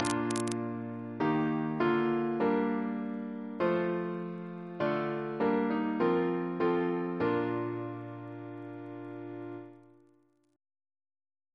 Single chant in B♭ Composer: George A. Macfarren (1813-1887) Reference psalters: ACB: 126; ACP: 223; H1940: 661; OCB: 52